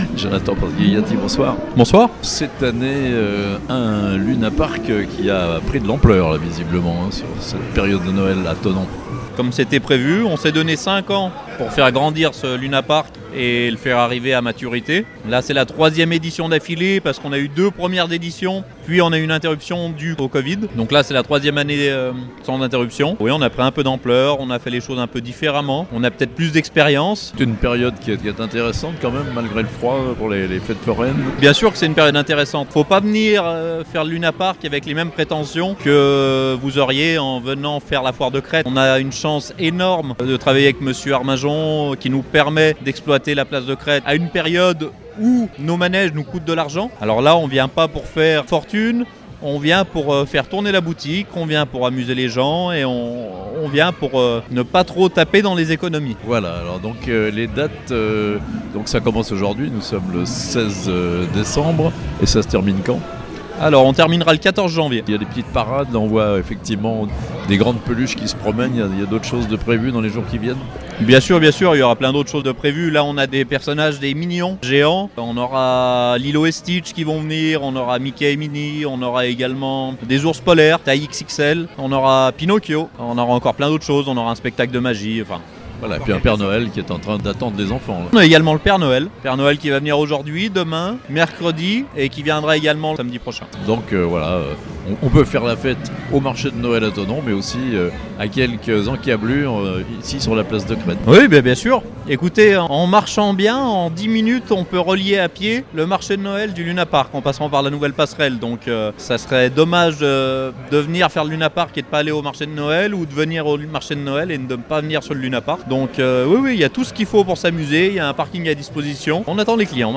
Thonon : le Luna Park de Noël est ouvert sur la place de Crête (interview)